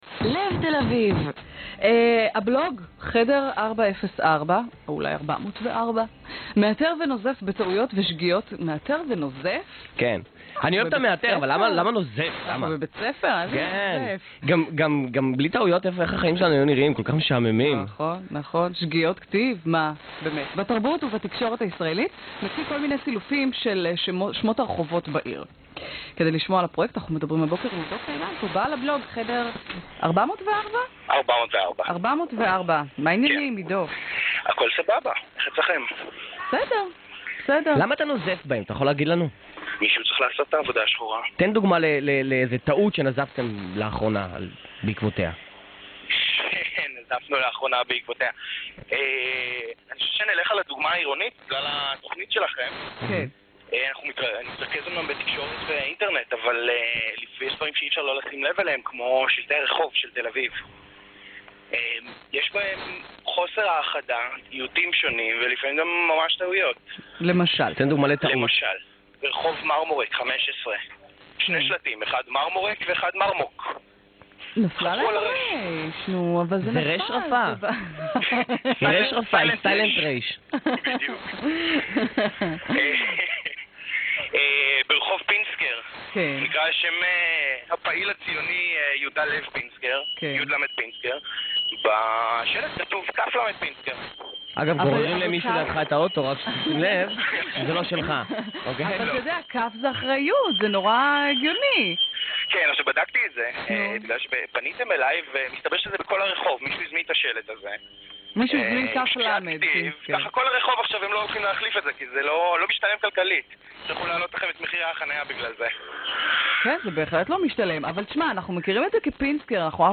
הראיון ברדיו תל אביב: